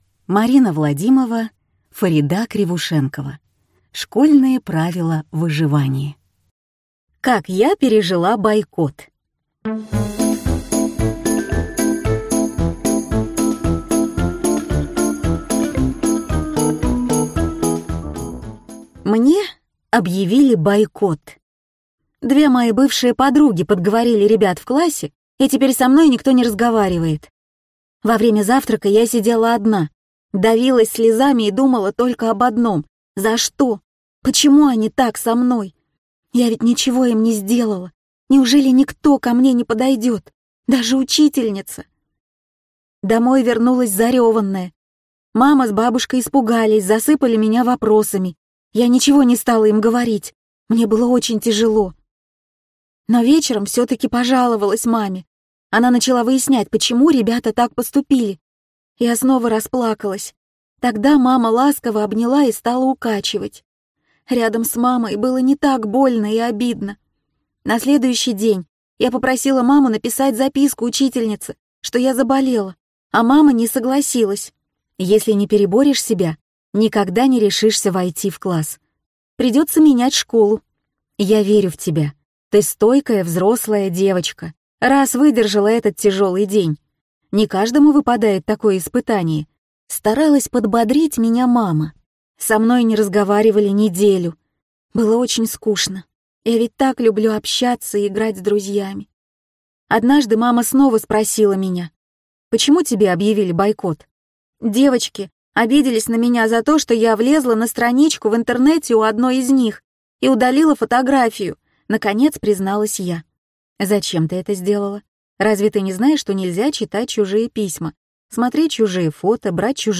Аудиокнига Школьные правила выживания | Библиотека аудиокниг
Прослушать и бесплатно скачать фрагмент аудиокниги